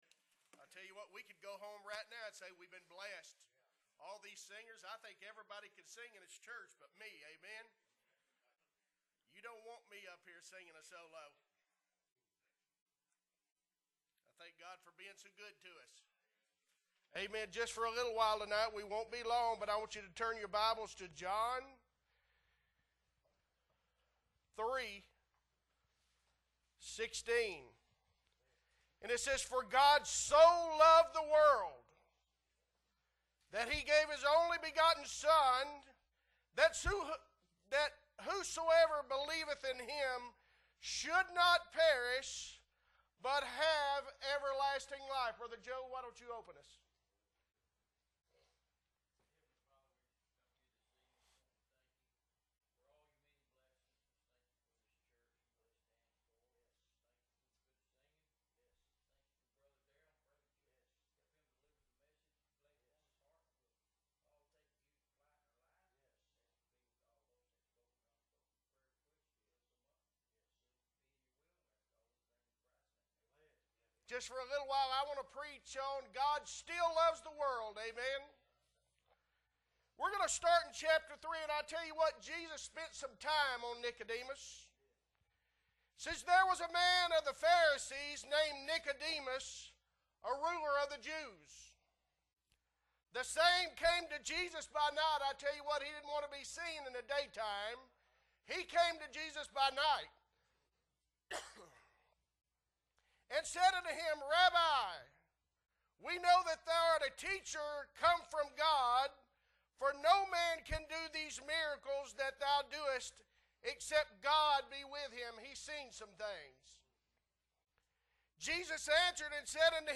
December 25, 2022 Evening service - Appleby Baptist Church
Sermons